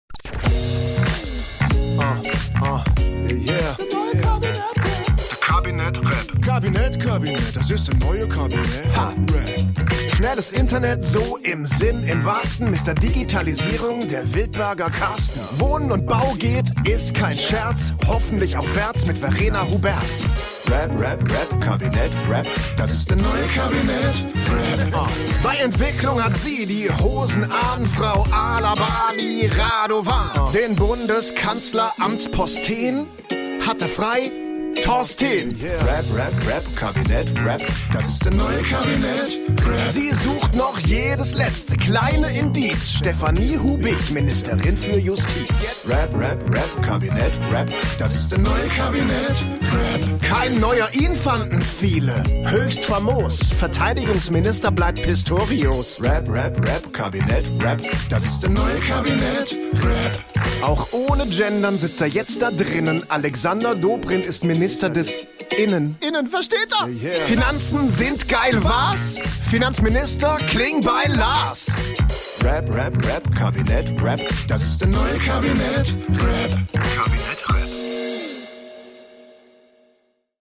Der Rap zum Erinnern